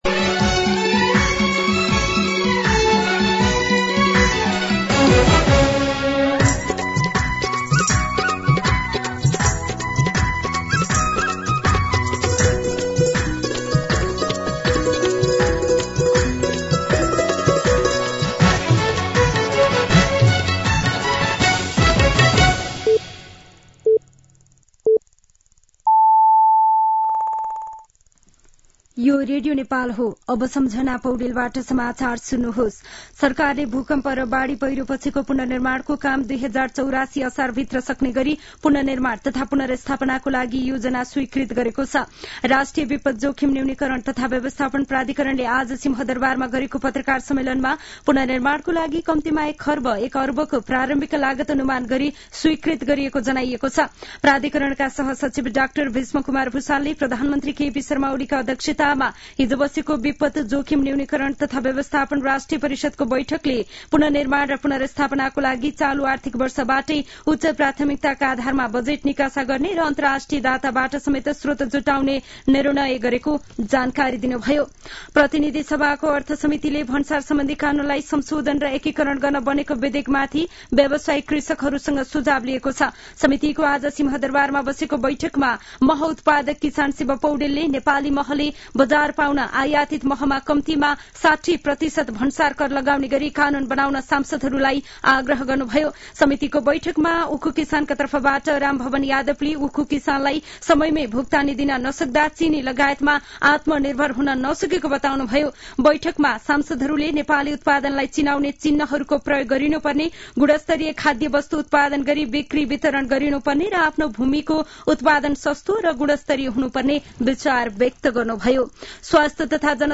दिउँसो ४ बजेको नेपाली समाचार : १ माघ , २०८१
4pm-news-.mp3